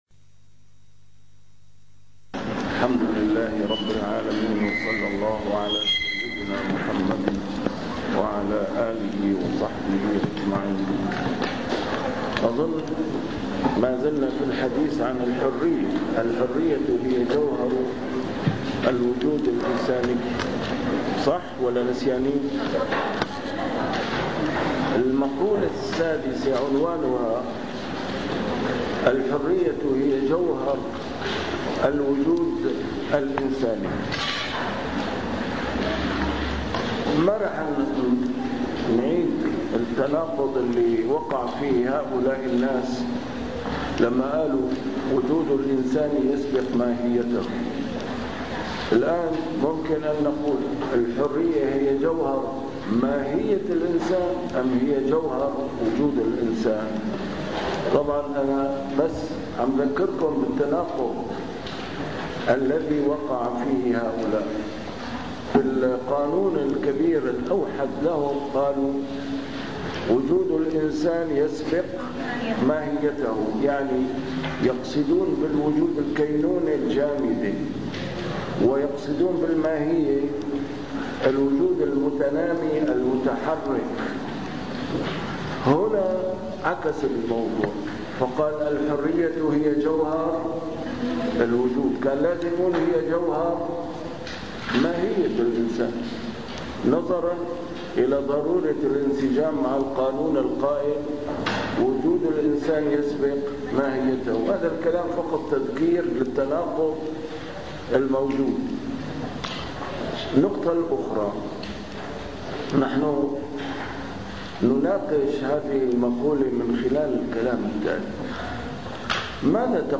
A MARTYR SCHOLAR: IMAM MUHAMMAD SAEED RAMADAN AL-BOUTI - الدروس العلمية - العقيدة الاسلامية والفكر المعاصر - المحاضرة السابعة: تتمة نقد المذهب الوجودي 3 والتعرف على نظريات التطور